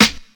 • Old School Dry Hip-Hop Snare Single Hit D Key 04.wav
Royality free snare drum sample tuned to the D note. Loudest frequency: 2862Hz
old-school-dry-hip-hop-snare-single-hit-d-key-04-kAS.wav